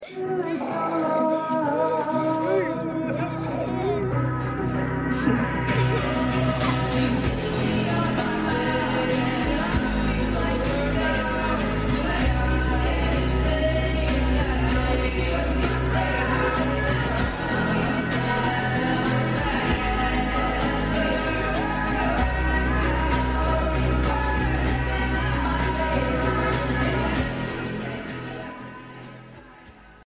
ignore the quality because the movie is old